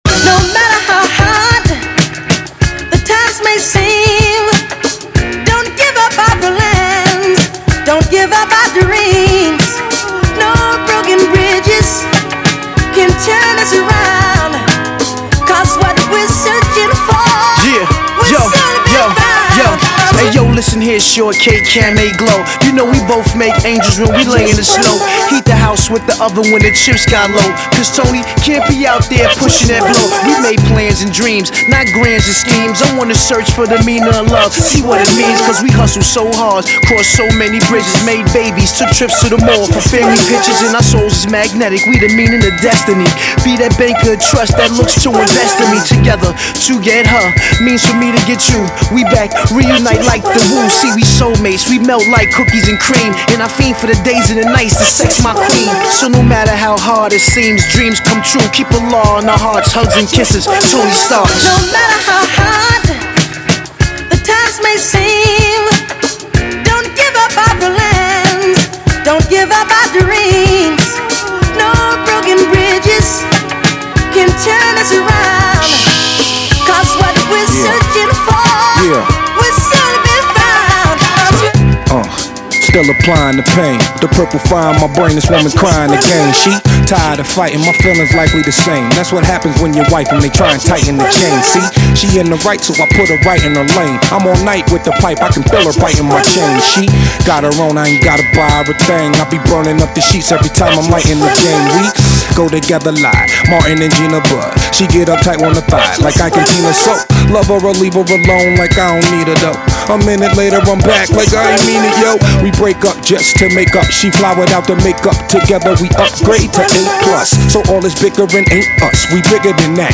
Some new smooth stuff
Hip Hop Music